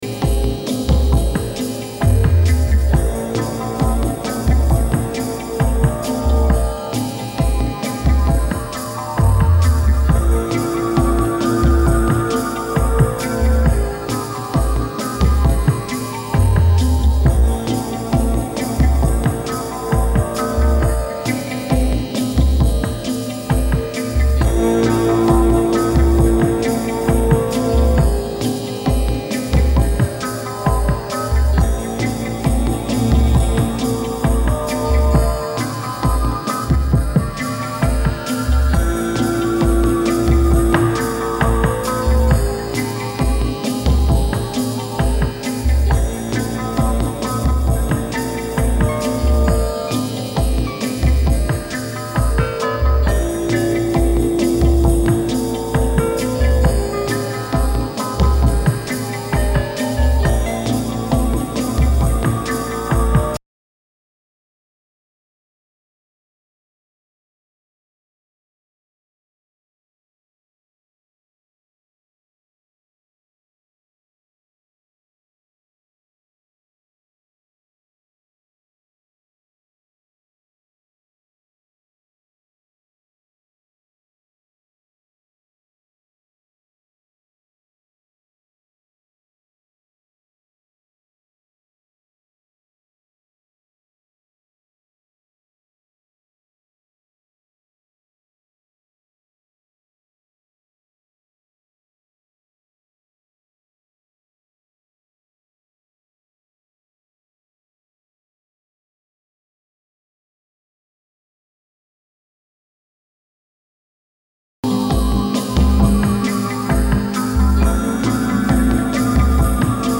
Electronix Techno Ambient